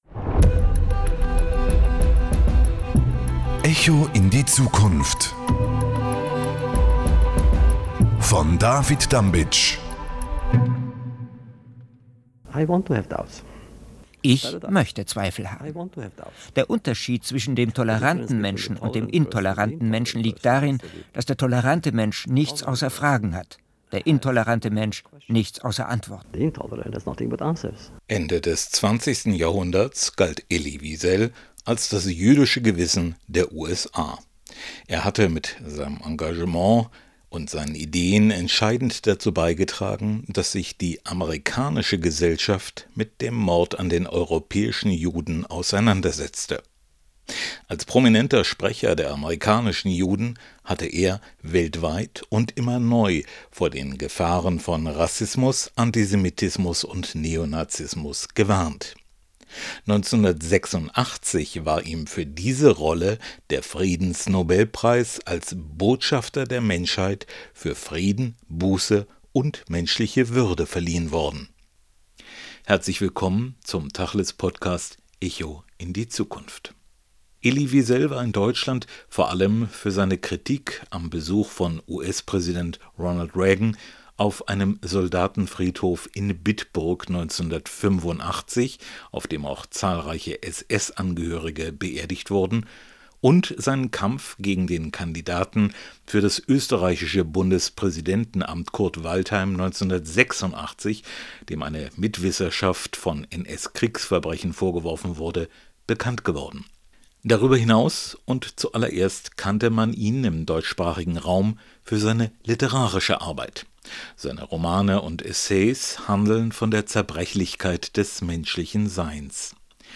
Mit Blick auf den internationalen Holocaust-Gedenktag und der Befreiung von Auschwitz vor 80 Jahren, präsentiert die aktuelle Ausgabe von «Echo in die Zukunft» ein Gespräch mit Friedensnobelpreisträger Elie Wiesel.